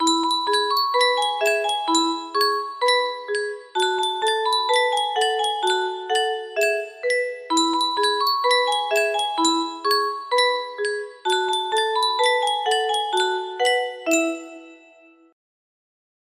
Yunsheng Caja de Musica - Tengo una Muñeca Vestida de Azul Y768 music box melody
Full range 60